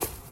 grass_footstep.wav